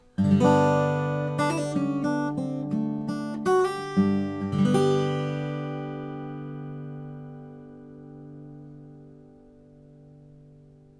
Since I have a small variety of mics, and four channels of recording capability, and a T-bar for mounting several mics in close proximity, I decided to attempt to compare some of these mics by recording a very brief clip of solo acoustic guitar.
In order to minimize proximity effect and reduce variations due to small differences in position, I placed the mics 18 inches from the guitar.
I play Hawaiian slack key nearly exclusively, so the guitar is tuned to Open G, and played with bare thumb and fingers.
The room is a rectangular drywall space, light carpeted floor, and two walls lined with vinyl LPs.
Countryman EM-101